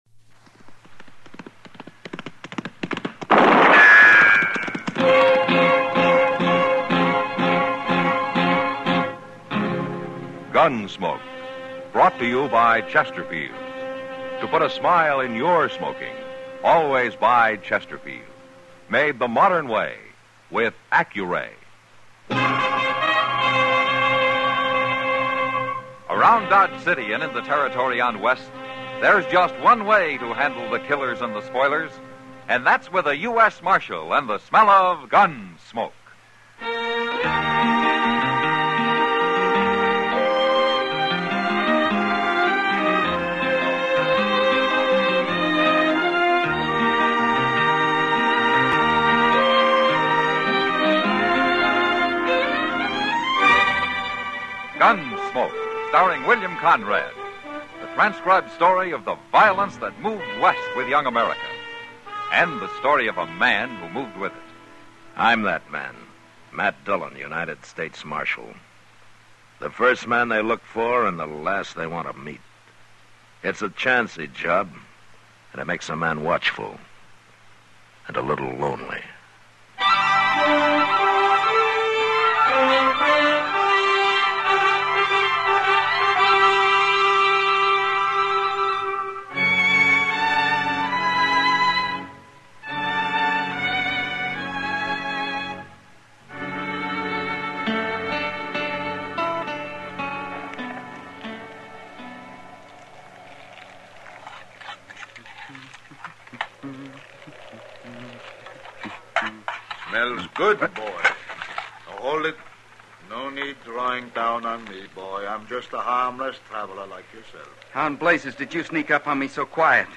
Gunsmoke is an American radio and television Western drama series created by director Norman Macdonnell and writer John Meston. The stories take place in and around Dodge City, Kansas, during the settlement of the American West. The central character is lawman Marshal Matt Dillon, played by William Conrad on radio and James Arness on television.